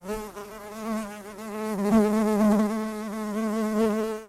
دانلود آهنگ مگس 3 از افکت صوتی انسان و موجودات زنده
دانلود صدای مگس 3 از ساعد نیوز با لینک مستقیم و کیفیت بالا
جلوه های صوتی